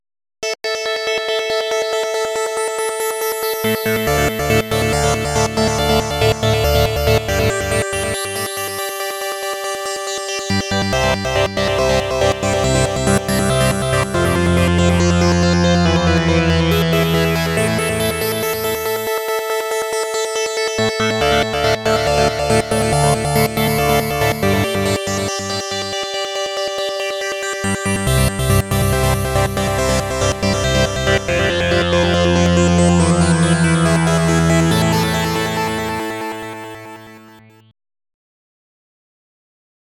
These songs are created with AutoCAD.